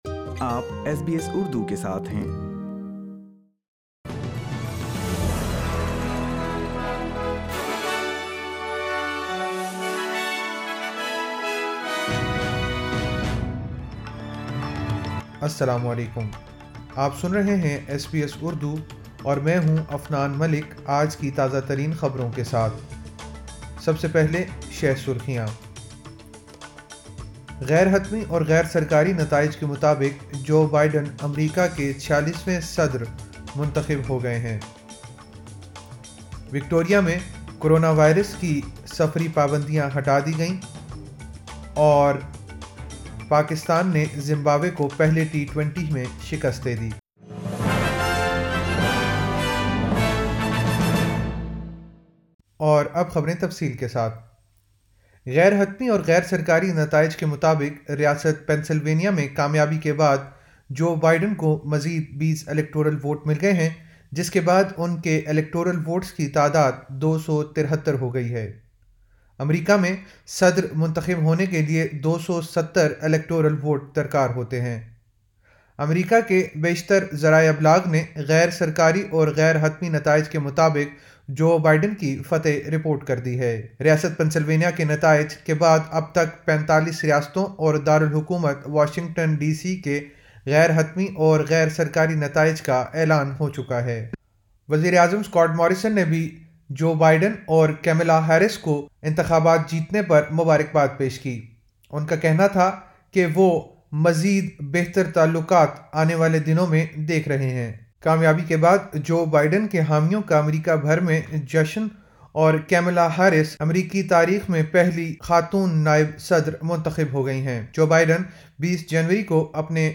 ایس بی ایس اردو خبریں 08 نومبر 2020